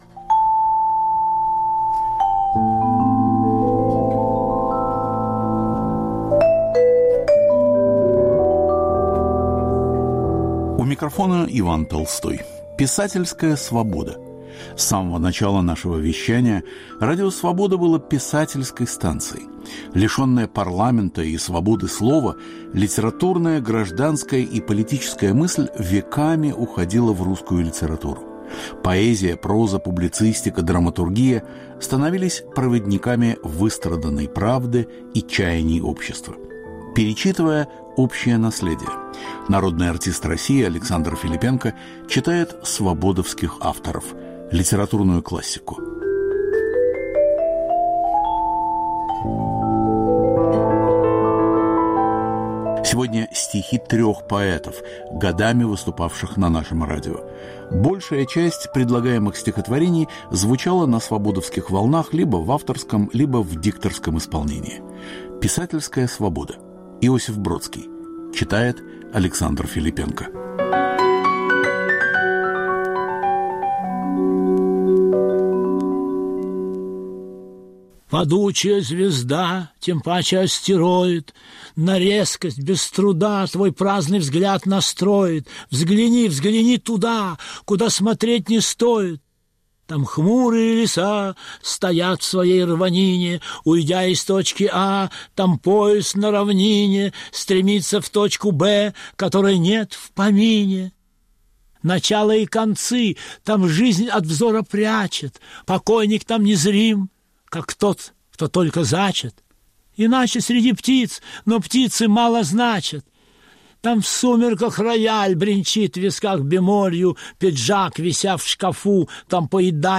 Народный артист России Александр Филиппенко читает стихи свободовских авторов
Цикл литературных подкастов мы заканчиваем поэтическим чтением.